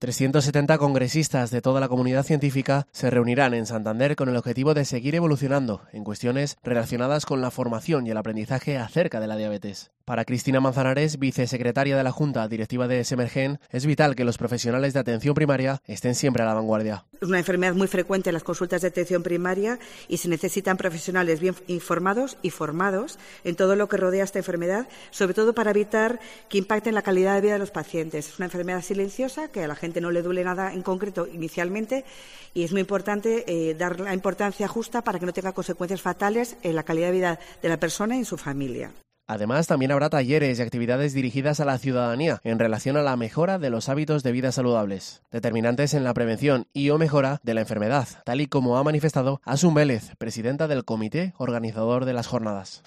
Presentación de las XI Jornadas Semergen